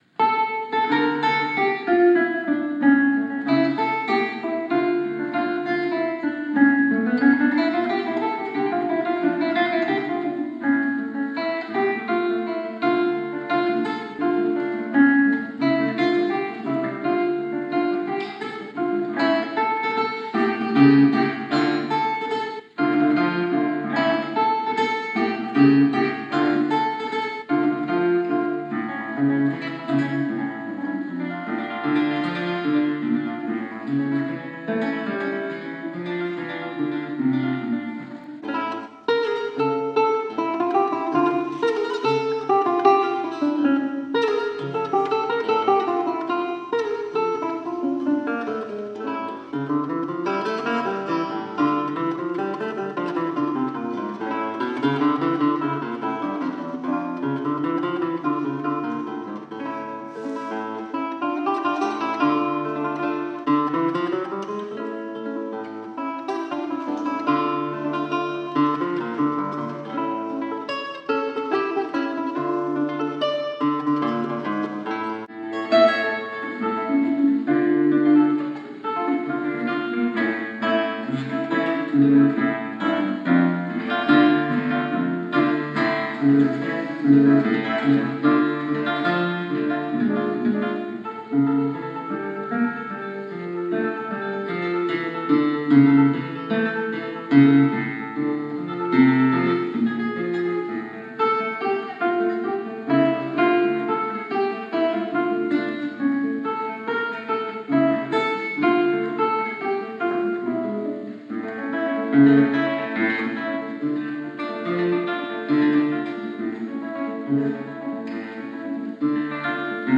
original Fabricatore guitar dating back to 1819 (live performance